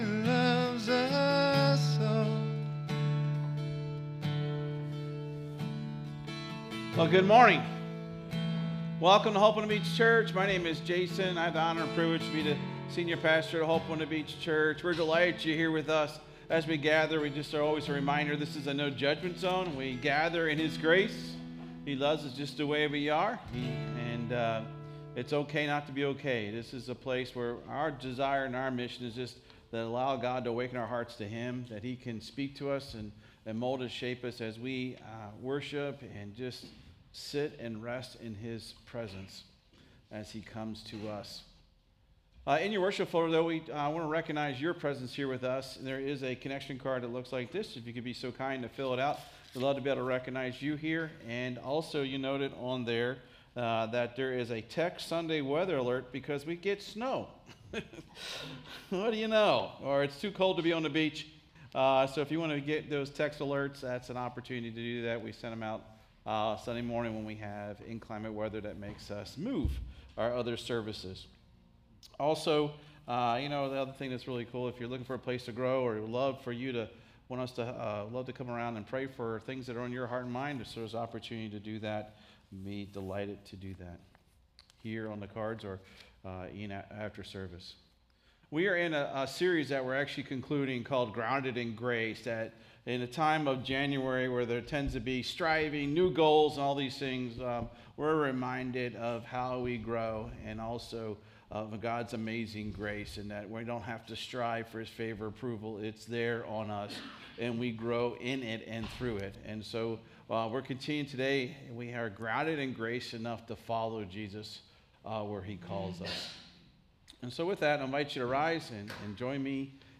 This sermon explores Jesus’ persistent call to follow Him and the transforming power of seeking Him with an open heart. From Philip’s immediate obedience to Nathanael’s honest doubt and sudden faith, we see that God never stops pursuing us with grace.